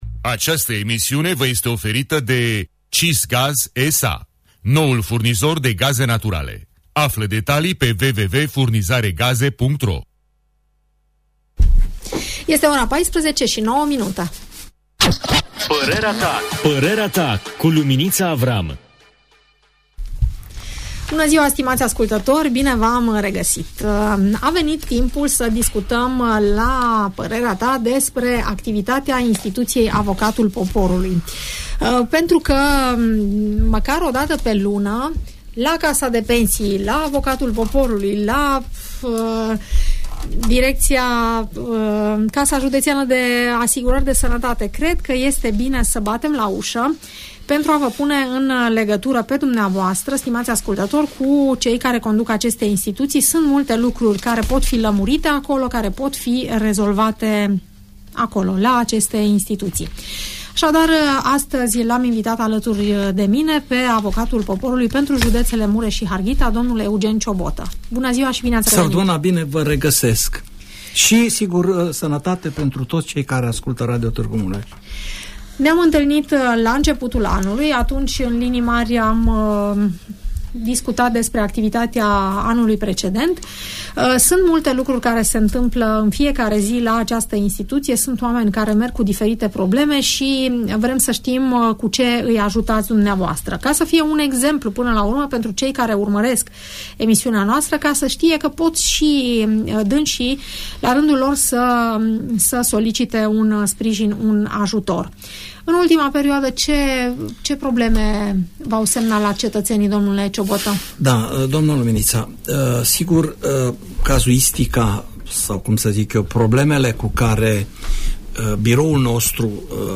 Dl Eugen Ciobotă, Avocatul Poporului pentru județele Mureș și Harghita, a avut la Radio Tg Mureș, din nou, audiență cu ascultătorii emisiunii ‘Părerea ta”. Află ultimele noutăți din activitatea insituției, urmărind emisiunea postată aici.